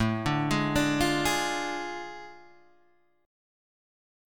A Major 7th Suspended 4th Sharp 5th